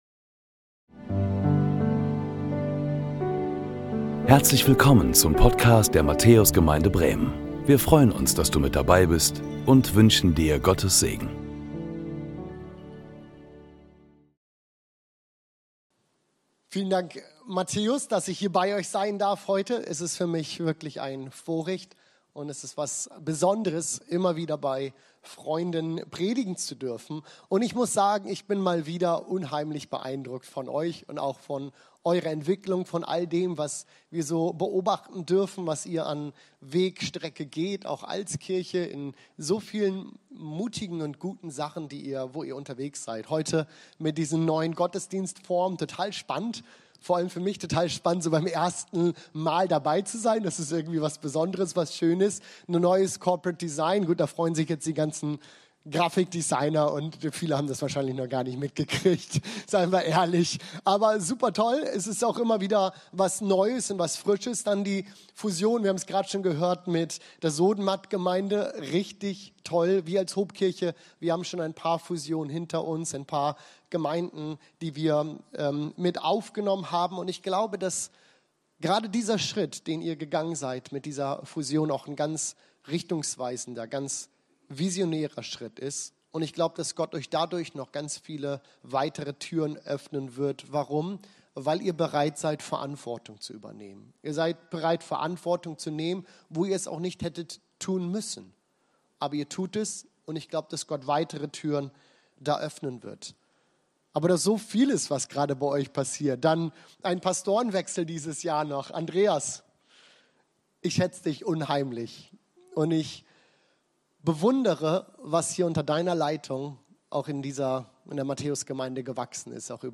Beschreibung vor 3 Monaten Willkommen zum Gottesdienst aus der Matthäus Gemeinde Bremen!